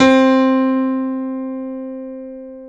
SG1 PNO  C 3.wav